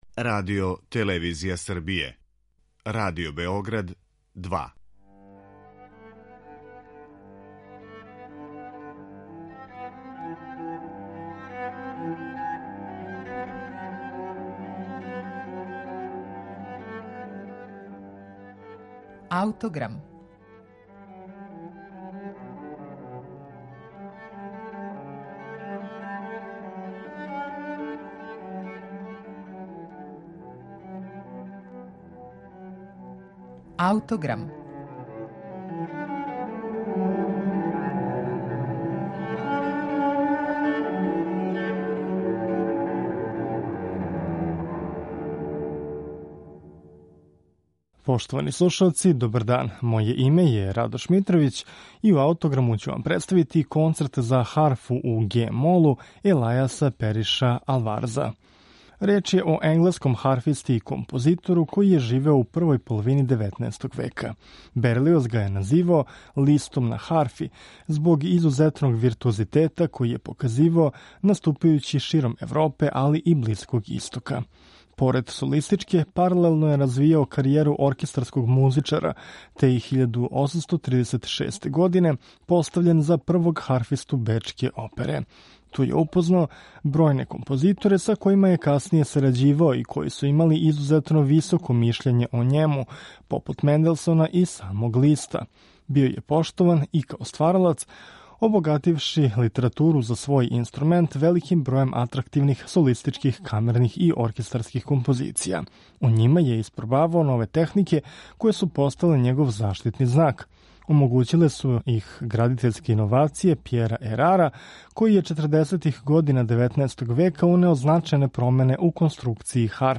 Представићемо Алварзов Концерт за харфу, настао 1842. године, који је заснован на изузетно виртуозном третману соло парта.